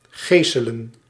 Ääntäminen
IPA: [py.niʁ]